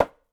Golpe de piedra contra una calabaza
Sonidos: Acciones humanas